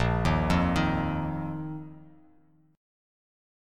A#sus4 chord